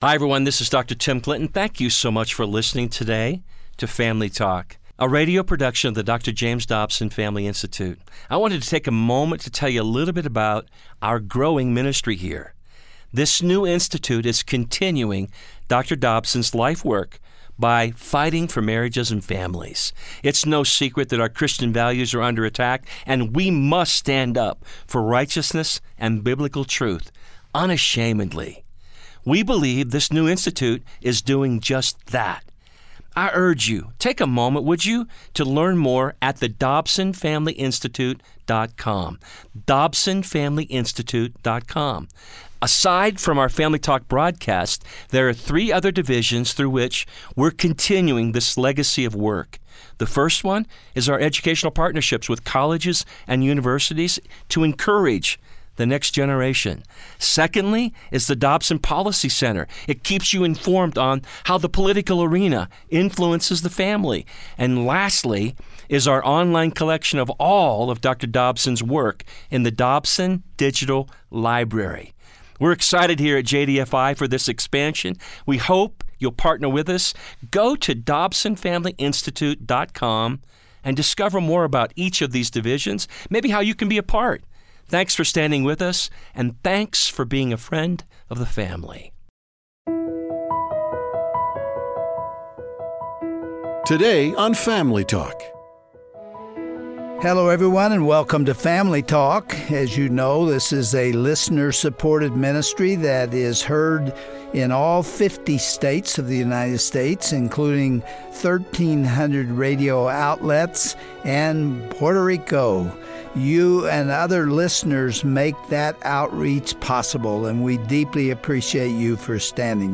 Dr. Dobson interviews former Alaska governor and Vice Presidential nominee, Sarah Palin. She explains how shes maintained her faith in the political spotlight, and emphasizes the importance of her family.
Host Dr. James Dobson
Following_Gods_Lead_An_Interview_with_Sarah_Palin_Part_1.wav